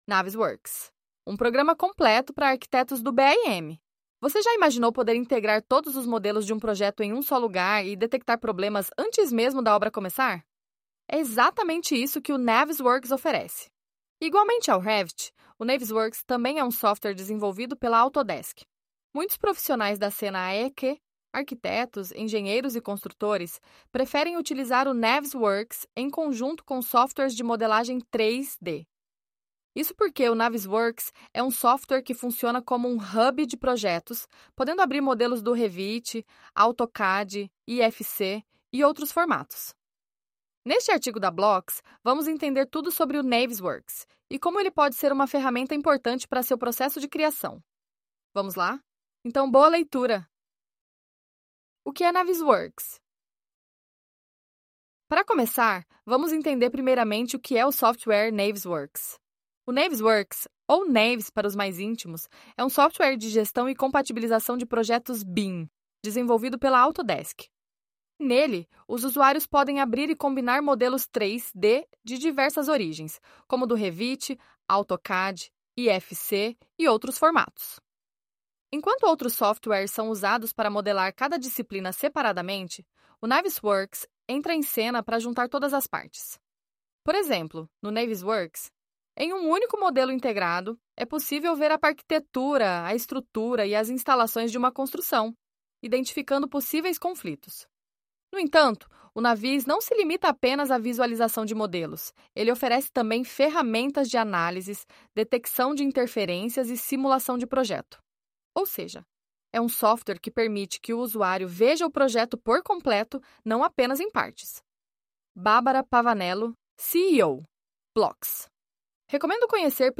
🎧 Sem tempo para ler? Então, dê um play e escute nosso artigo!